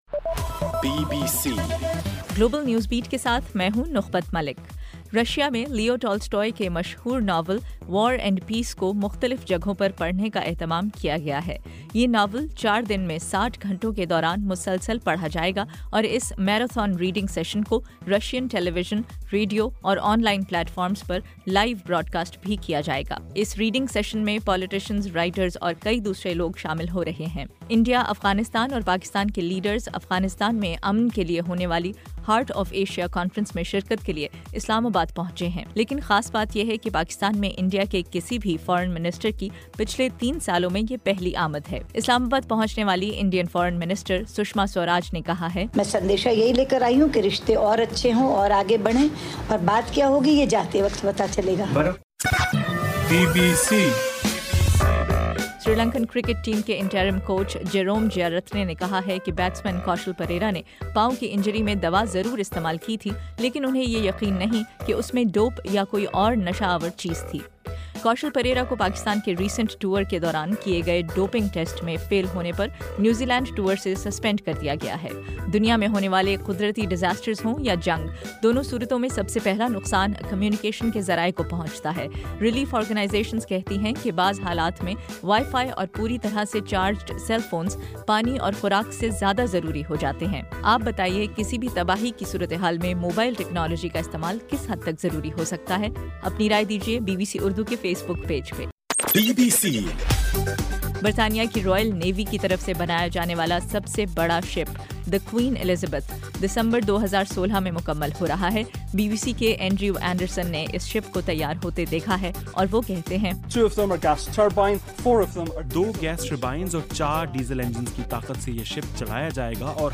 دسمبر 8: رات 10 بجے کا گلوبل نیوز بیٹ بُلیٹن